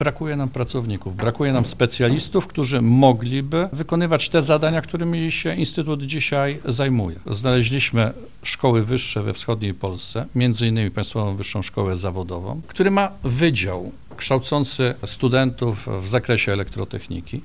mówił podczas konferencji prasowej